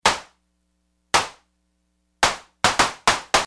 AnalogClap Old style analog beatbox (TR-808) hand clap.
AnalogClap.mp3